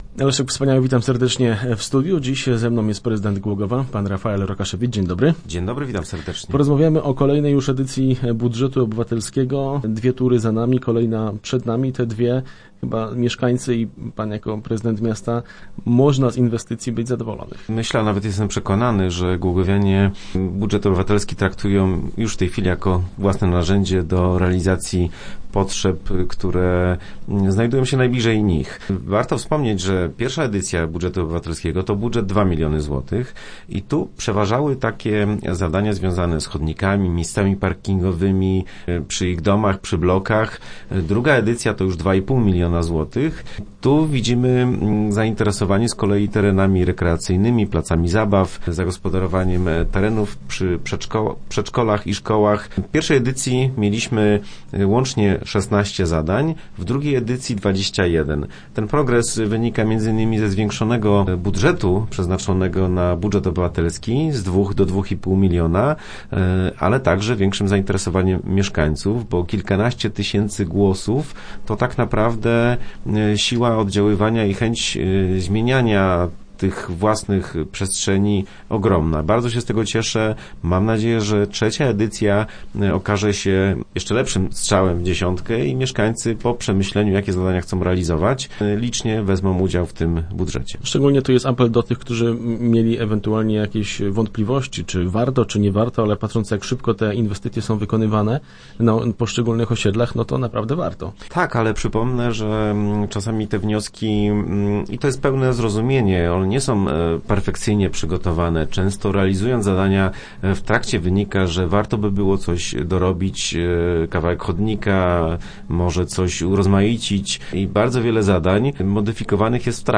Władze Głogowa przygotowują się do trzeciej edycji Budżetu Obywatelskiego. Już od 16 maja mieszkańcy będą mogli w ratuszu składać swoje projekty. Na ten temat w środę w studiu rozmawialiśmy z prezydentem Głogowa Rafaelem Rokaszewiczem.